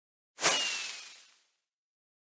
Minecraft Sound Effects MP3 Download Free - Quick Sounds